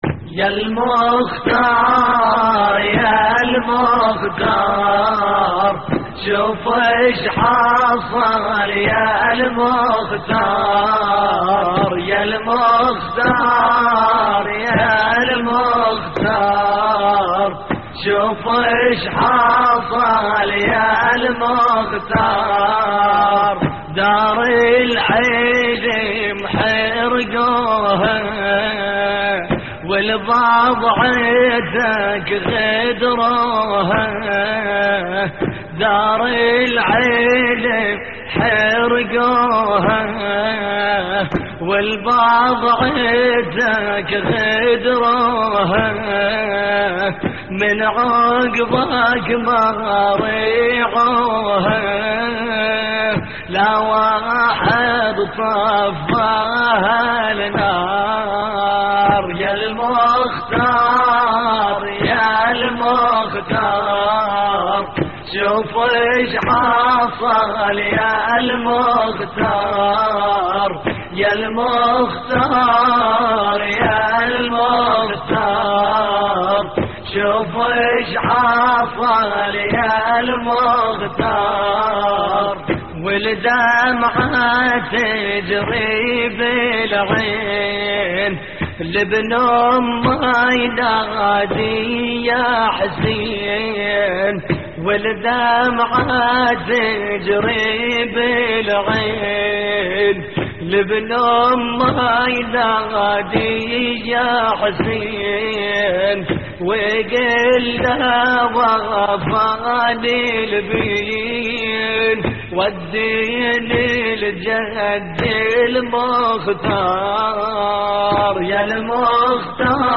الرادود
استديو